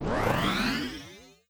Exemple à partir d’un son de train :
Son avant transformation